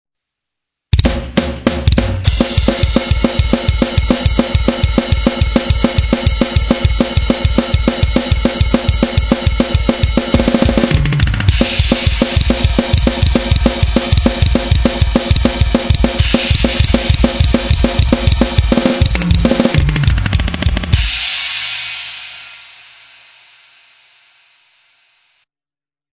ちなみに、このサイトでアップしている管理人自身によるデモ演奏は(別にたいしたこともないですが｡｡｡｡)、よりツーバスの魅力を伝えるため、或いは足の踏み順などを解りやすくするため、左のバスドラと右のバスドラの音がスピーカーの左右からそれぞれ別々に聴こえるようにレコーディングミックスしてあります(※本来はバスドラの音はレコーディングにおいてのパン(スピーカーから聴こえてくる定位置)は真ん中です)。
デモ演奏用にスタジオを借りると時間もお金も大変なので、このサイトでのデモ演奏はエレドラによるレコーディングです
コア系？
スラッシュと似てるけどちょっと違うリズムパターン
スラッシュ系と比べると、ちょっとベタベタした感じです。
デモ音源の後半(上の譜面の下のパターン)では拍に一発ペタンと入るバスドラムをパワーアップさせる形でツーバスで連打しているパターンでは、
リズムのベタベタ感が消えて、独特の疾走感や殺気が出せるので、メタルでもよく使われると思います。